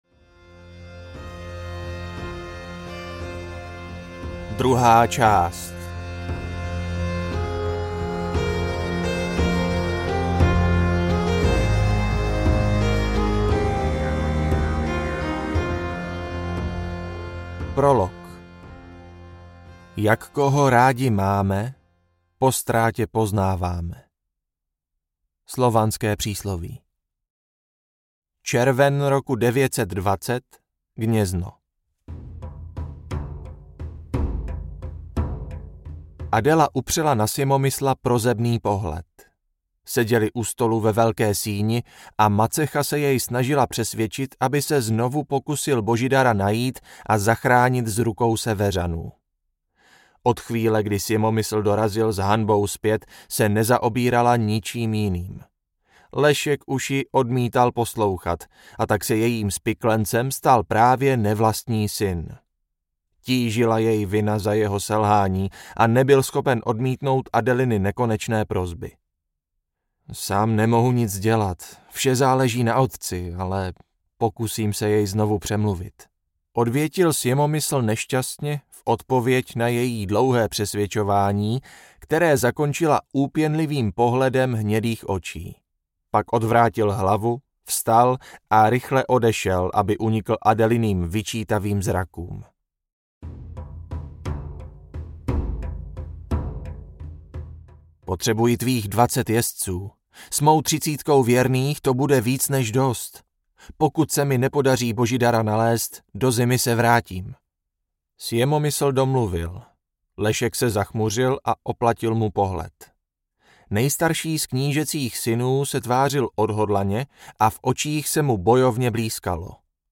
Ewraker II audiokniha
Ukázka z knihy